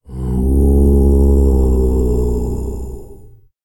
TUVANGROAN01.wav